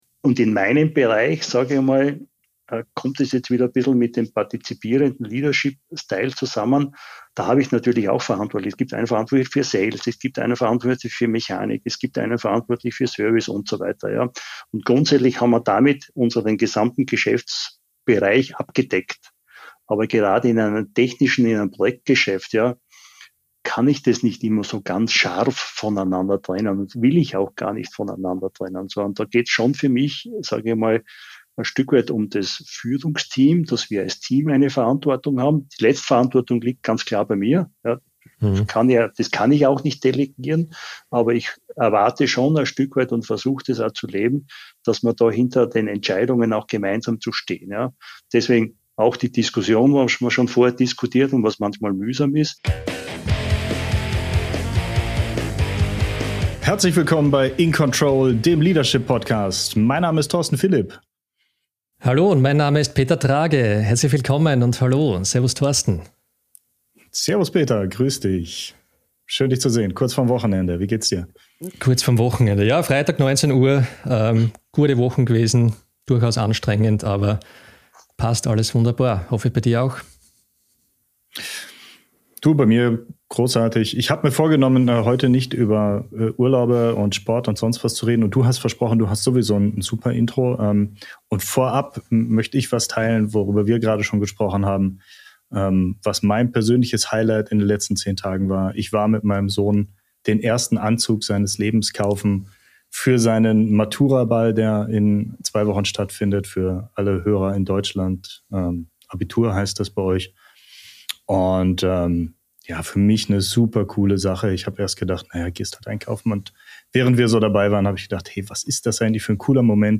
Themen dieser Folge: • Persönliche Entwicklung und Karriere im internationalen Umfeld • Führung im Spannungsfeld von Konzern, Region und Kultur • Leadership im ländlichen Raum vs. globale Arbeitstrends • Verantwortung, Haltung und Entscheidungsfindung im Großkonzern Jetzt reinhören: IN CONTROL – der Leadership Podcast – ehrliche Gespräche über Führung, Kultur und persönliche Entwicklung.